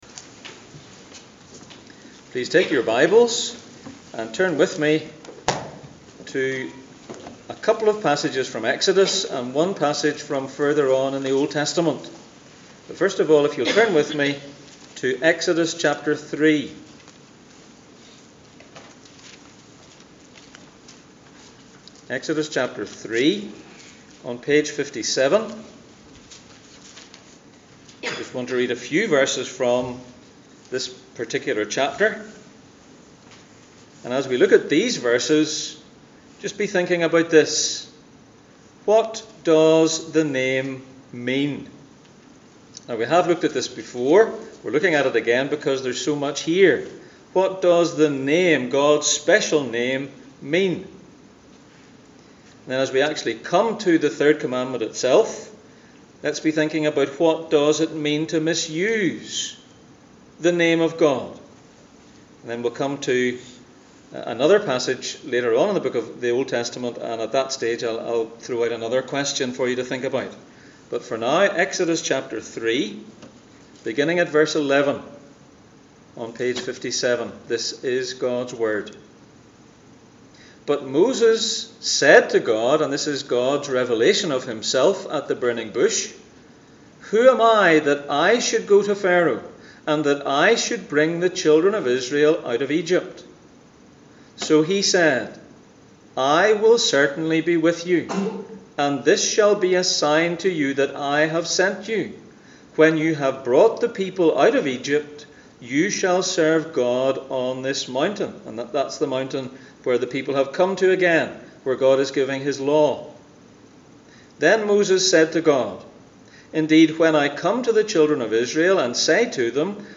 Gods instructions for life Passage: Exodus 3:11-15, Exodus 20:7, Malachi 1:6-14, Exodus 6:2-5, John 8:58 Service Type: Sunday Morning